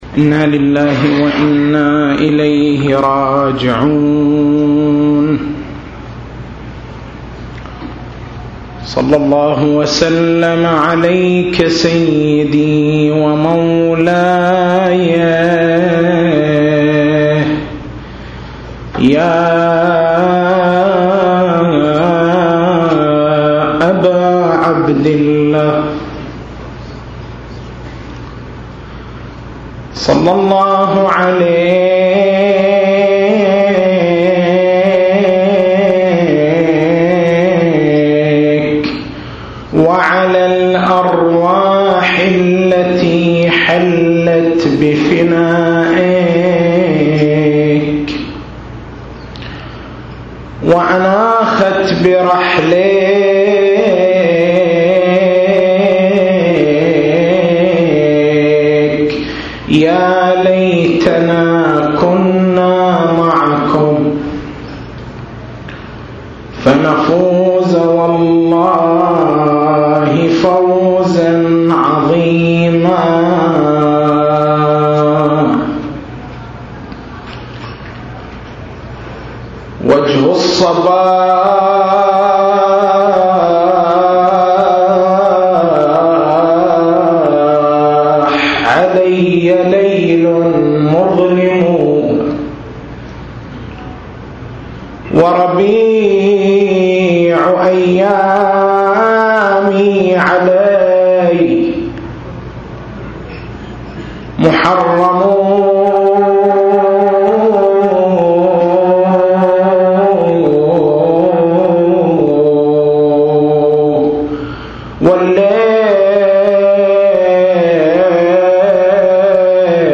نص المحاضرة